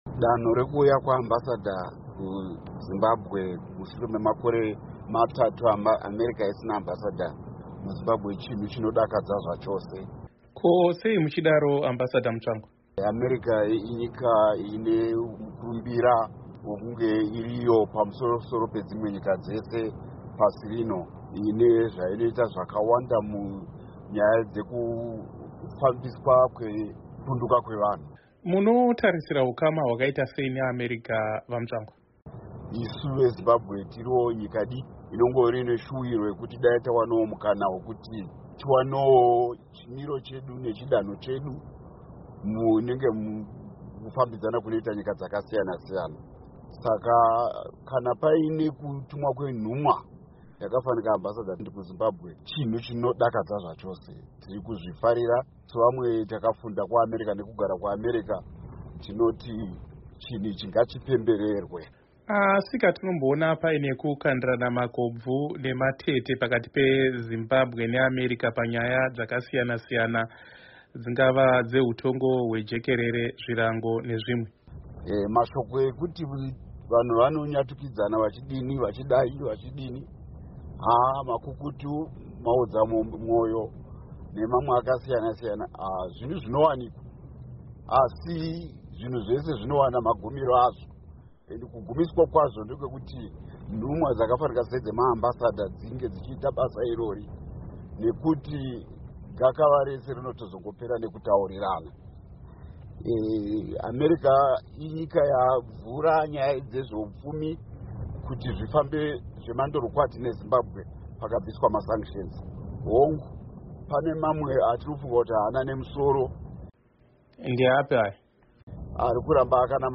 Hurukuro naVaChris Mutsvangwa